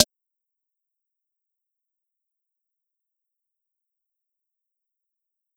Snare (Glow).wav